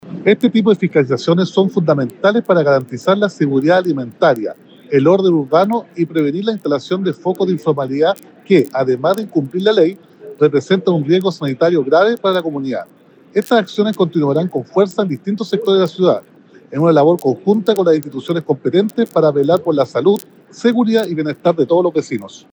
Roberto-Neira-alcalde-de-Temuco-1-1.mp3